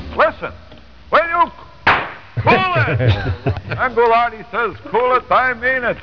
Exasperated Ghoulardi!